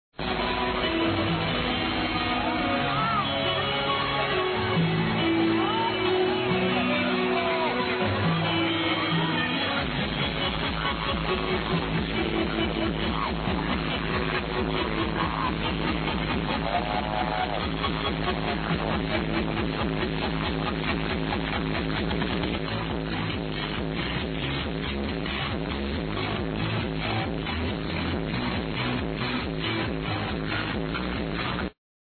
it has a breakdown w/ guitar & strings